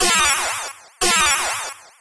a blood-curdling cry, the shriek of a beast forgotten by both nature and humanity.
alisiadragoonscreech.mp3